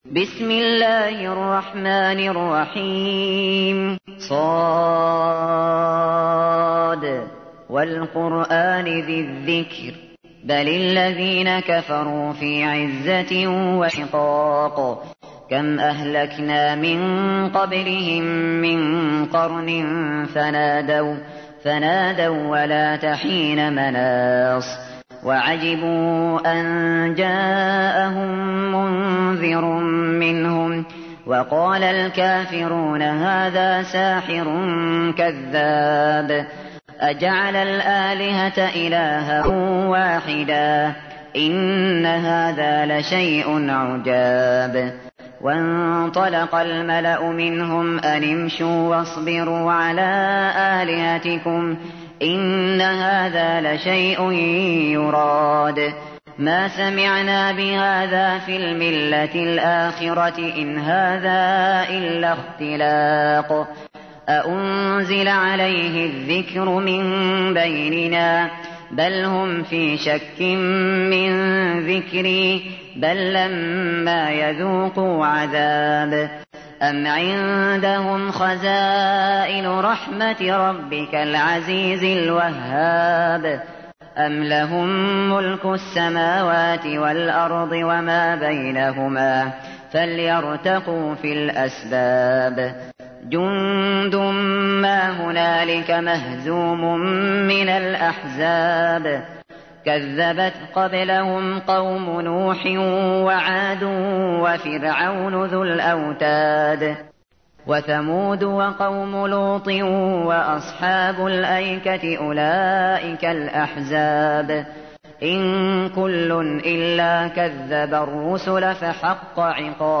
تحميل : 38. سورة ص / القارئ الشاطري / القرآن الكريم / موقع يا حسين